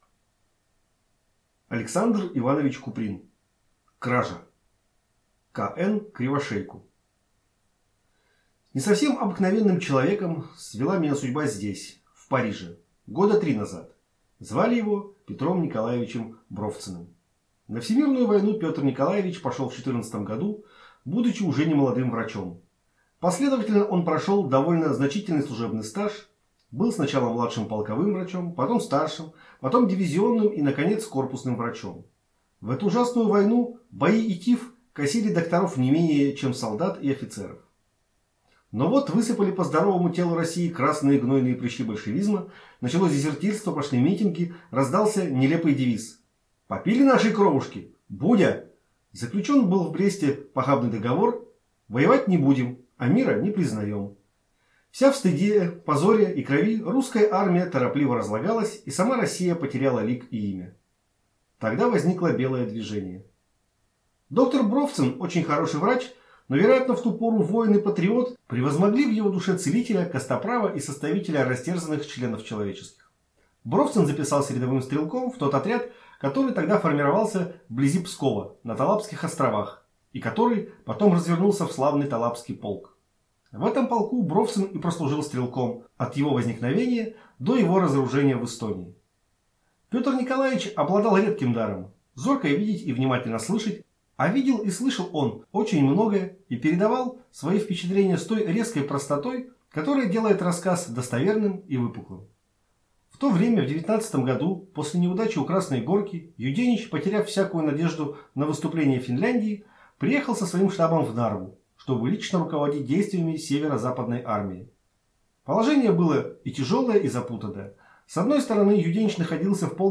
Аудиокнига Кража | Библиотека аудиокниг